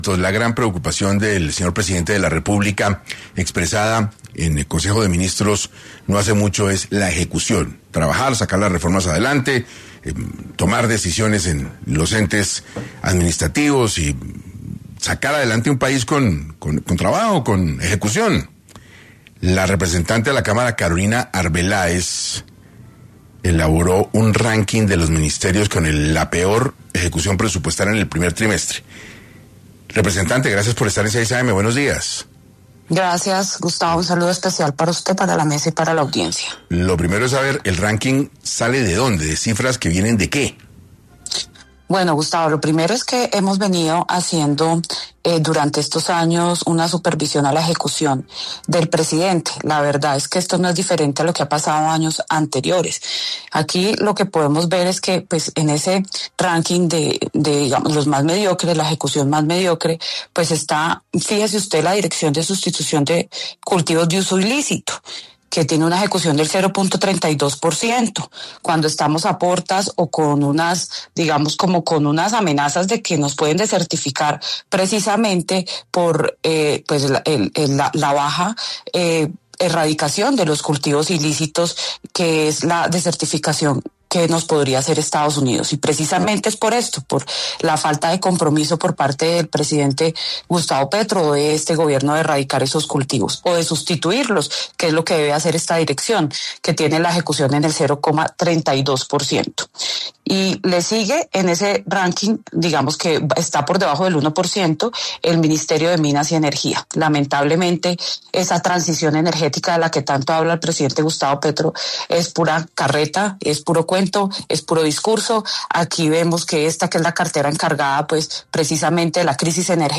Carolina Arbeláez, representante a la Cámara, estuvo en 6AM para profundizar sobre las entidades con el peor desempeño en ejecución presupuestal, en el primer trimestre del año.
Arbeláez pasó por los micrófonos de 6AM, para abordar los resultados del informe.